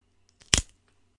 骨头扣：1号包 " 骨头扣1
描述：紧缩
Tag: 动作声音 骨扣 裂缝 血腥 战斗 紧缩